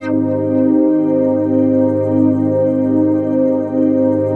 TRANCPAD25-LR.wav